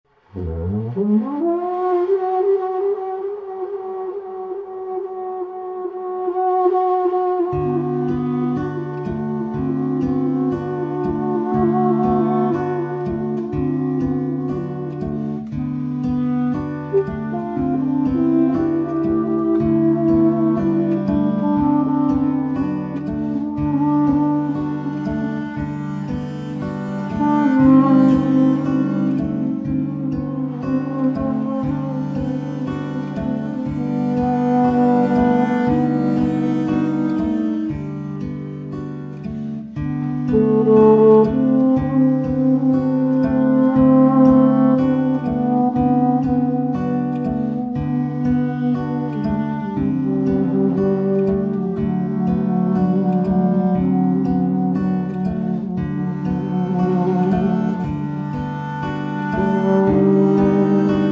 clarinette, cor de basset